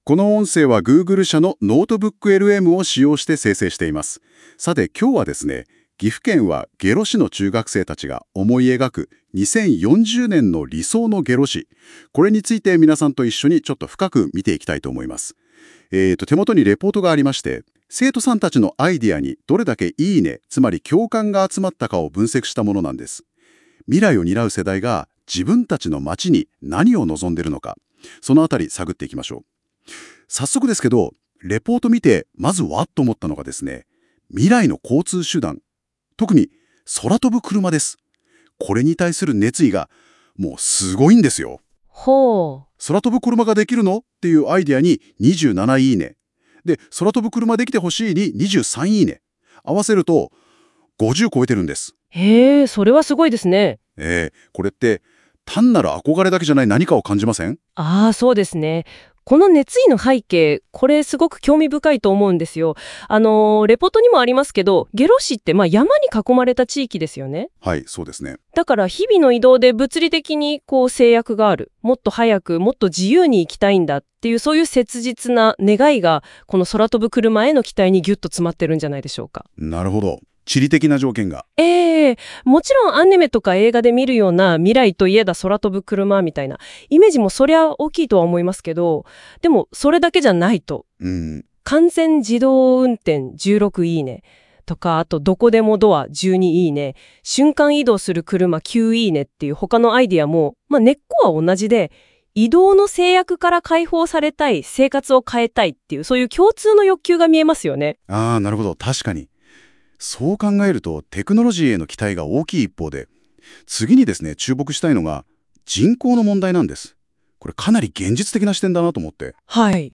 当ページの内容を生成AIによって作成した音声概要を聞くことができます。